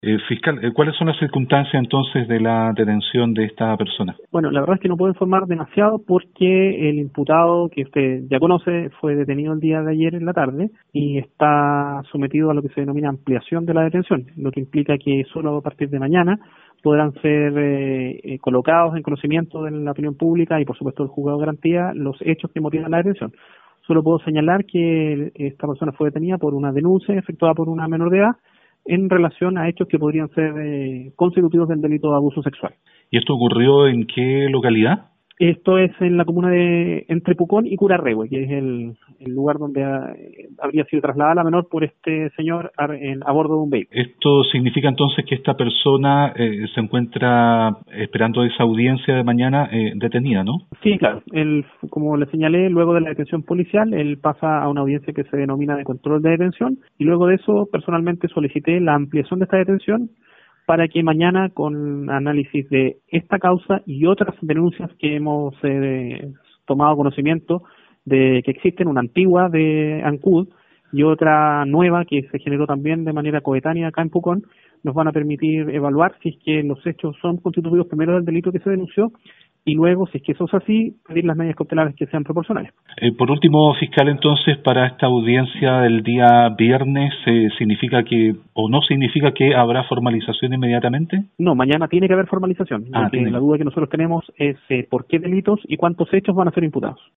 02-FISCAL-PUCON-.mp3